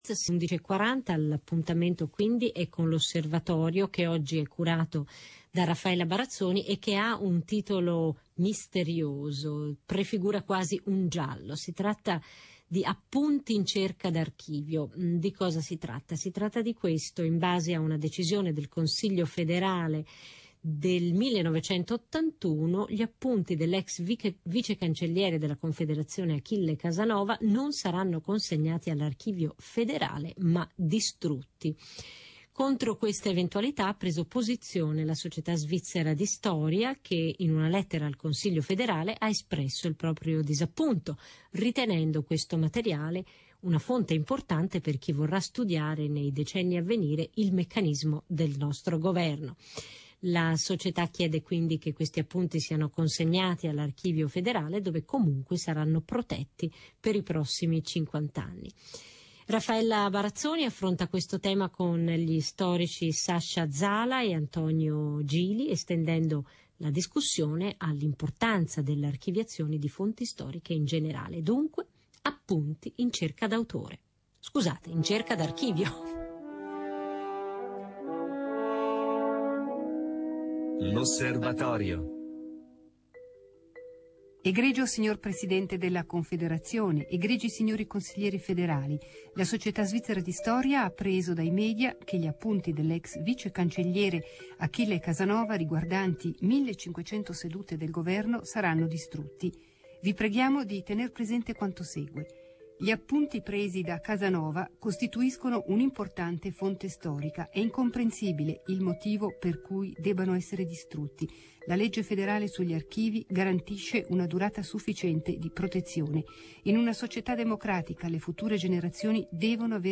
Ciò che il giornalismo non capisce. Appunti in cerca d’archivio. Intervista